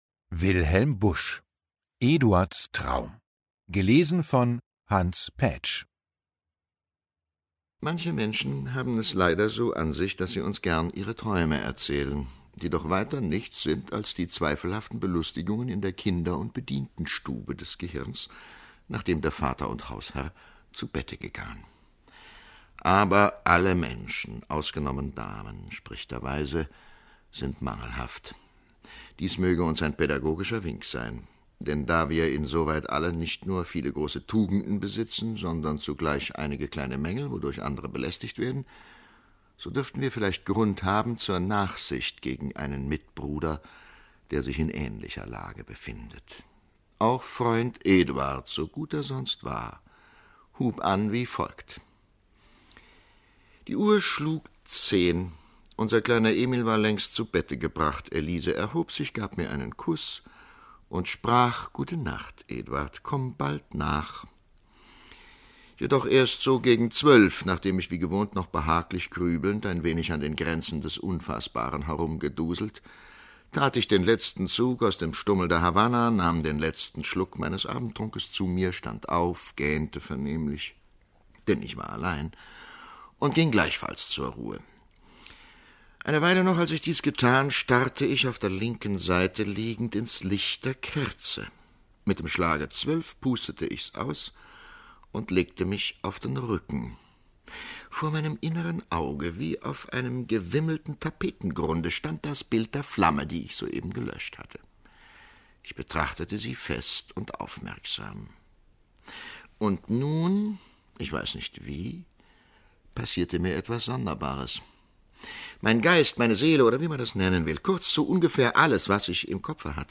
Lesung mit Hans Paetsch (1 mp3-CD)
Hans Paetsch (Sprecher)